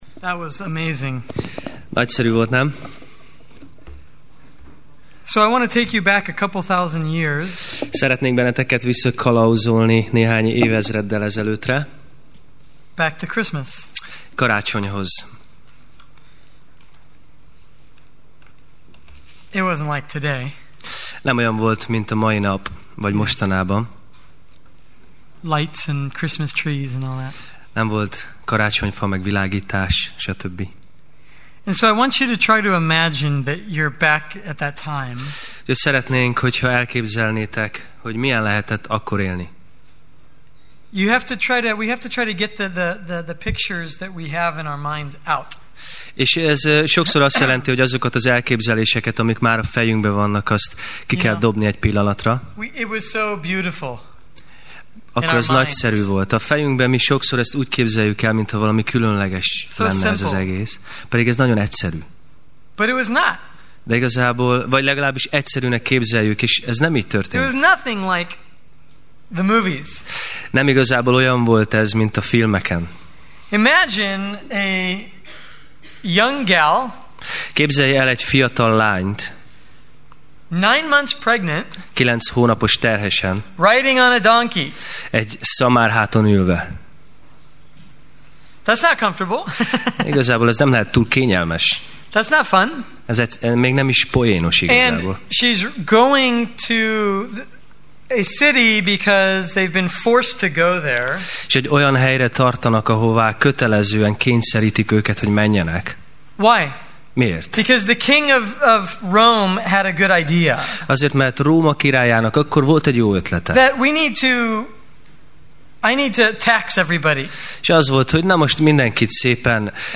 Sorozat: Tematikus tanítás Alkalom: Vasárnap Este